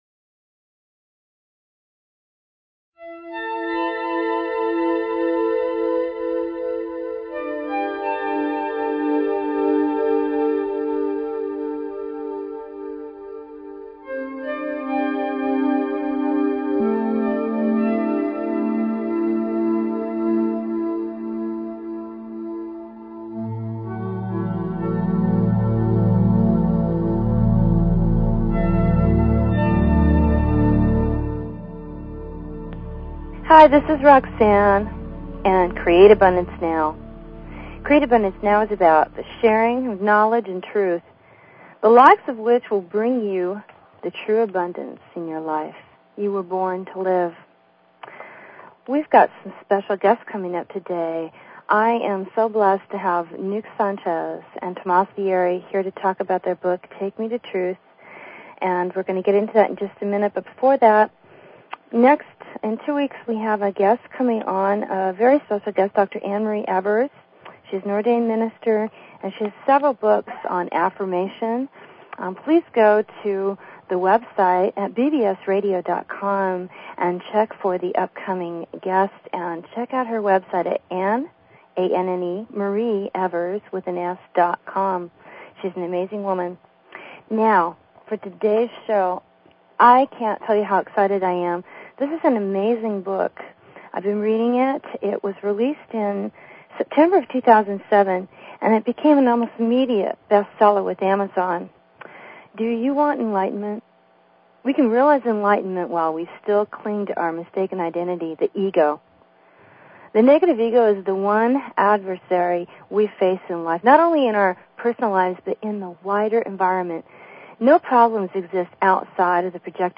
Talk Show Episode, Audio Podcast, Create_Abundance_Now and Courtesy of BBS Radio on , show guests , about , categorized as